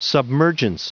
Prononciation du mot submergence en anglais (fichier audio)